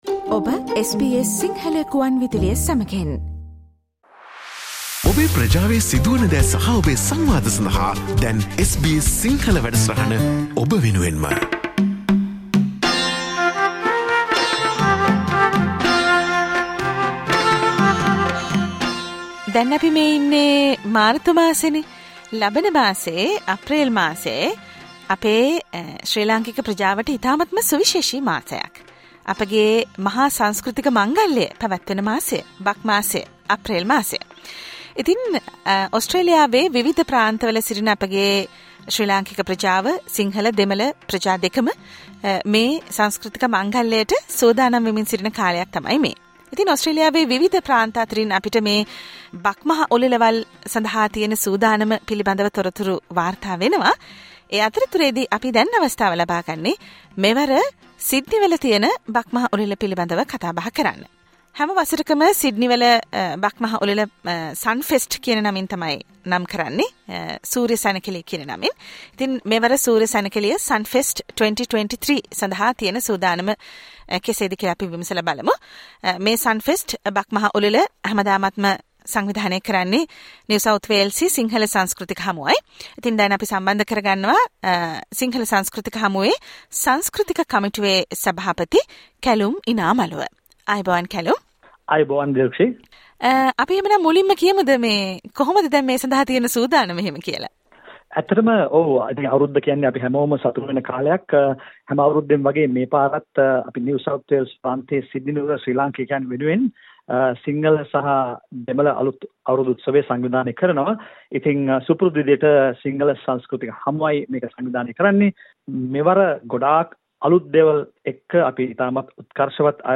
SBS Sinhala Radio interview on 'Sunfest 2023' - The Sydney New Year Festival_ Australia